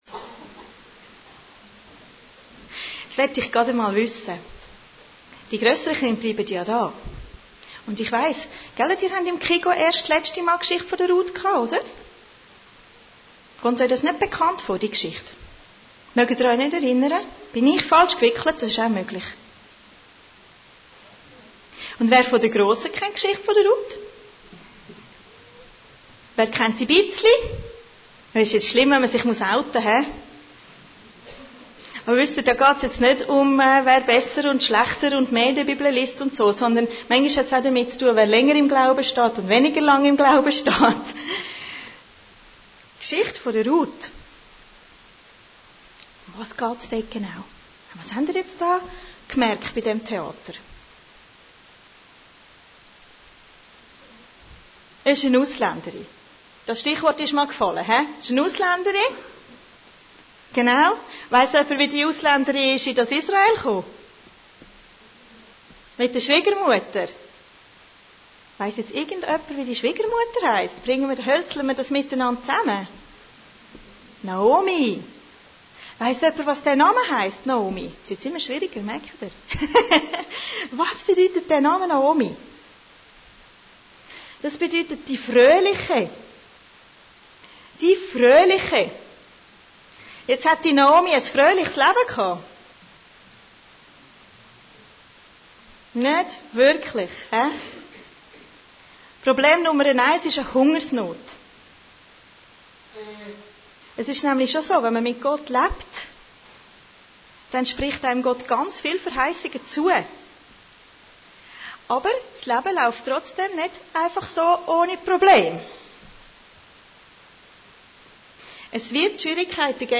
Predigten Heilsarmee Aargau Süd – Ruth und Boas